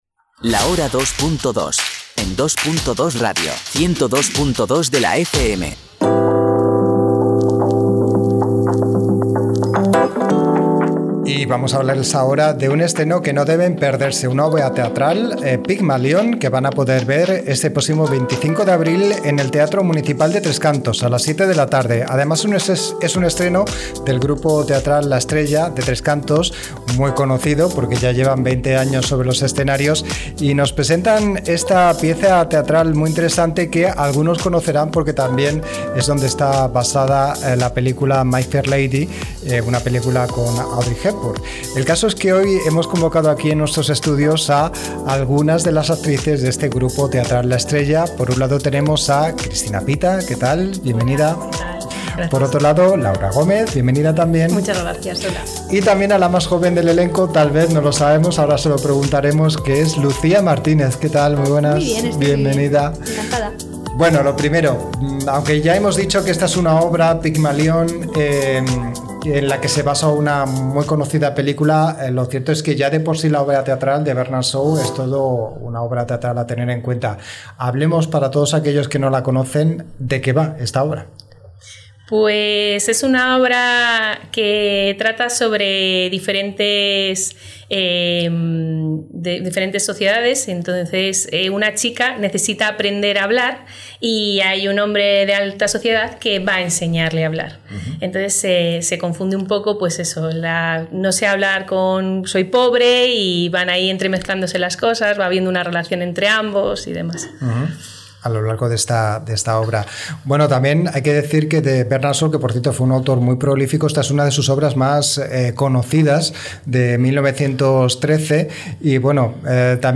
ENTREVISTA-GRUPO-TEATRAL-LA-ESTRELLA-EDIT-1.mp3